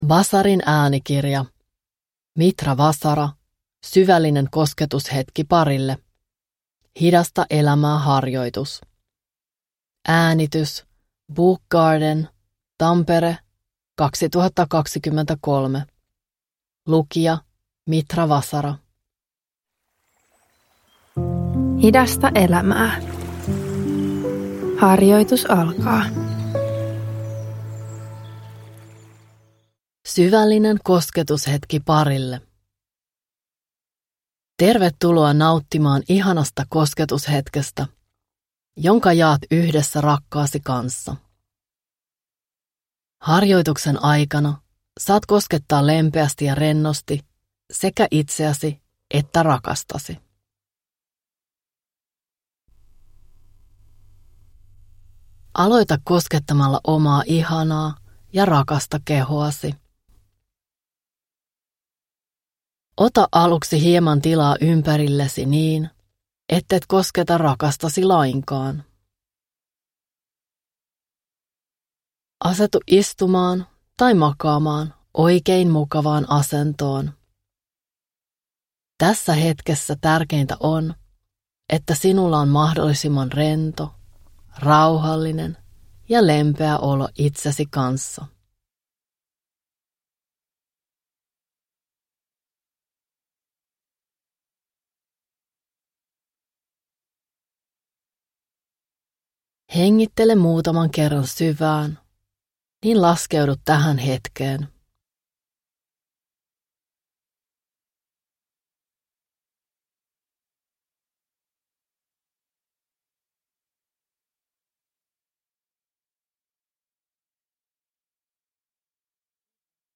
Syvällinen kosketushetki parille – Ljudbok – Laddas ner
Parihetki-harjoitus